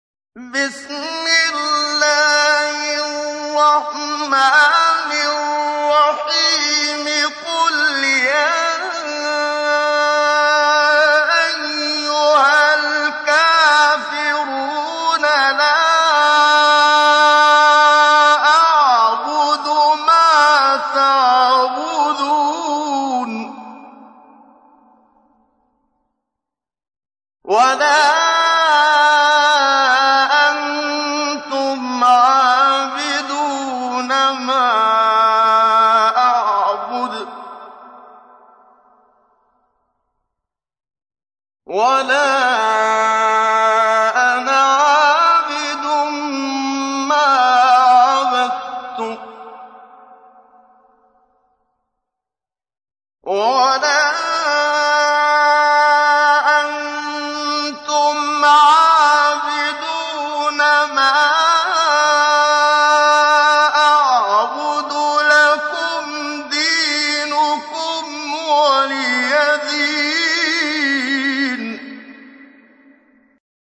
تحميل : 109. سورة الكافرون / القارئ محمد صديق المنشاوي / القرآن الكريم / موقع يا حسين